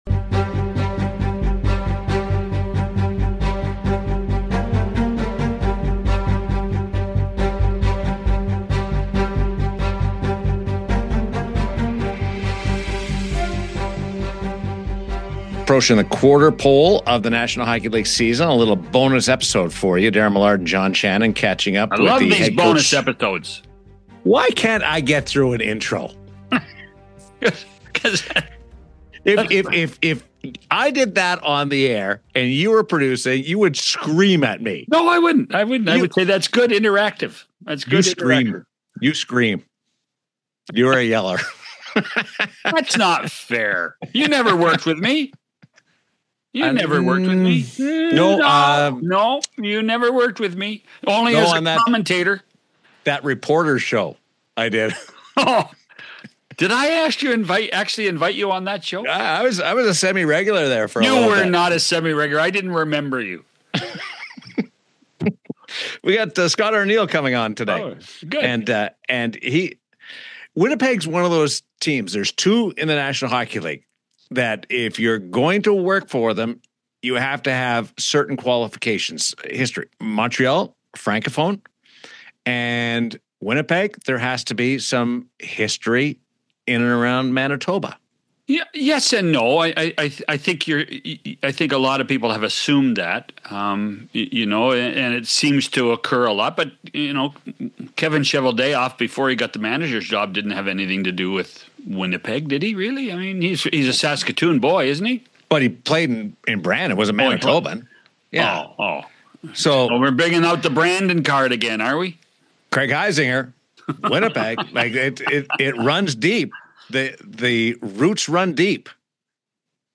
Scott Arniel, head coach of the Winnipeg Jets, joins John Shannon and Daren Millard on 100% Hockey to reflect on his path behind the bench and the evolution of coaching in today’s NHL.